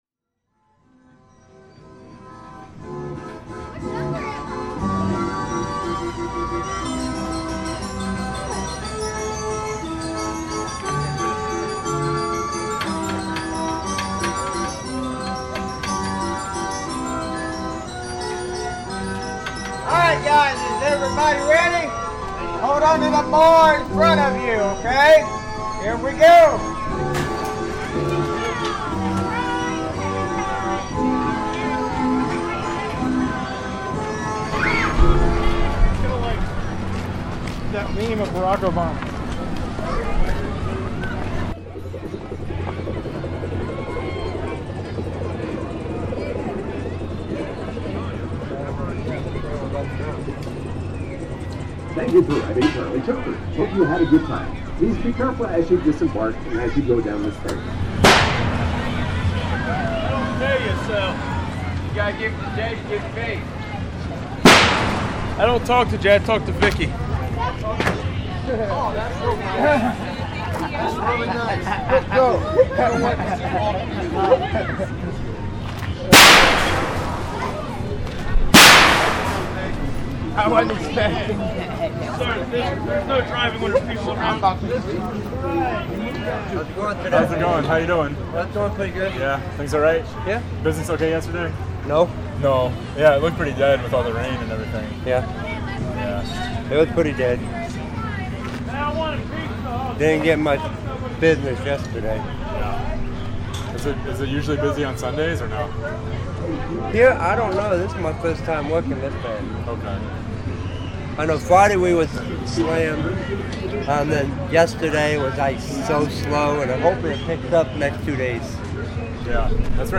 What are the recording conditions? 1-soundwalk-for-start.mp3